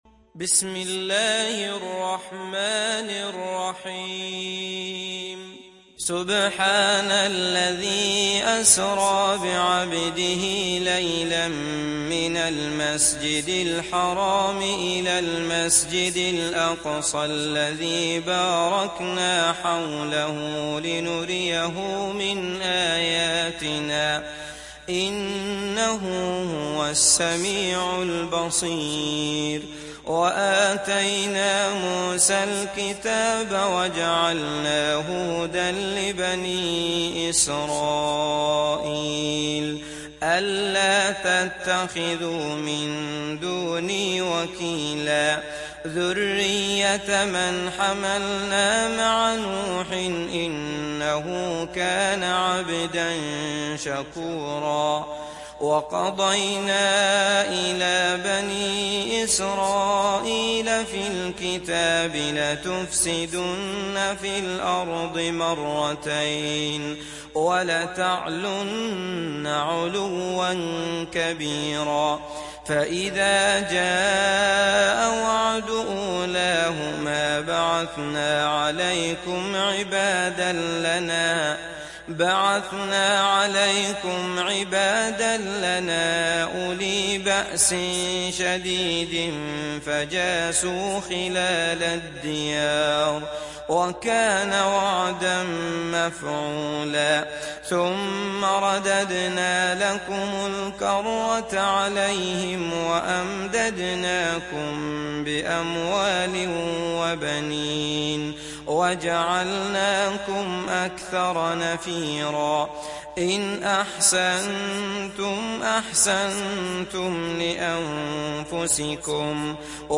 تحميل سورة الإسراء mp3 بصوت عبد الله المطرود برواية حفص عن عاصم, تحميل استماع القرآن الكريم على الجوال mp3 كاملا بروابط مباشرة وسريعة